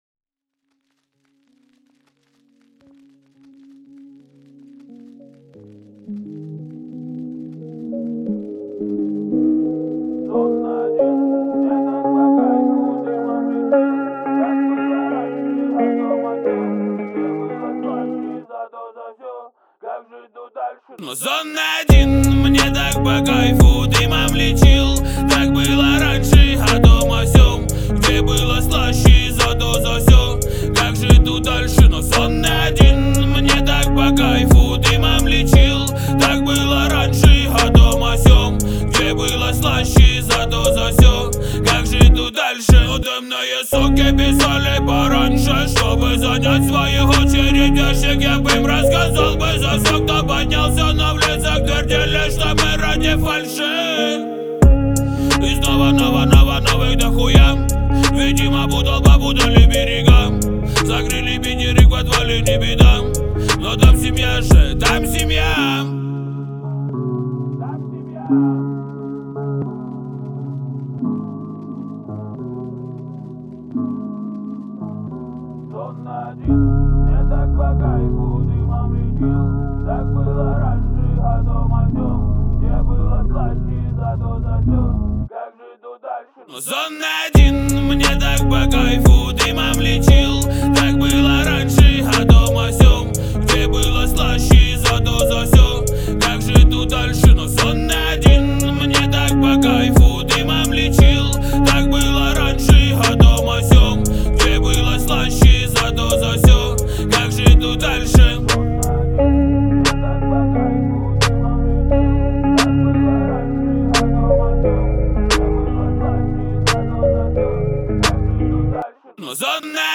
это трек в жанре инди-поп